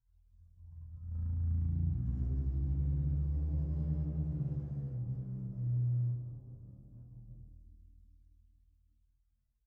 bassdrum_rub3_v1.wav